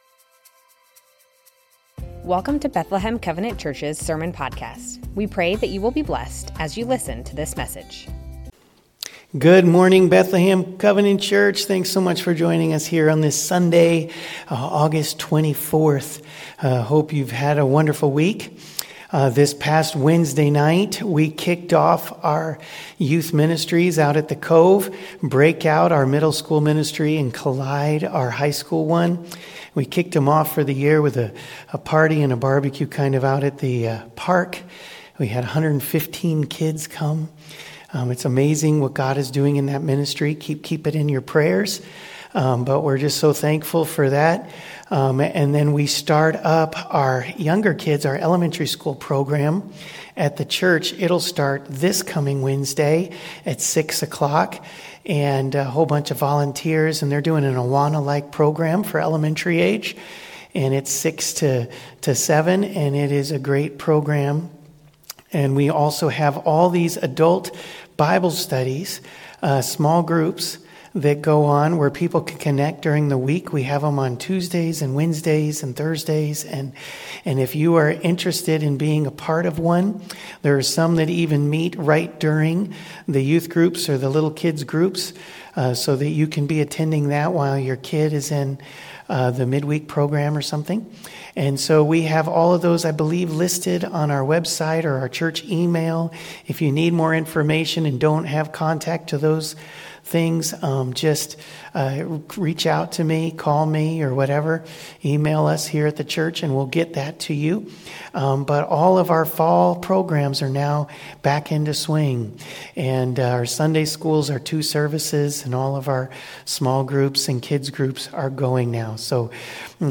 Bethlehem Covenant Church Sermons The Names of God - Adonai Aug 24 2025 | 00:34:18 1x 00:00 / 00:34:18 Subscribe Share Spotify RSS Feed Share Link Embed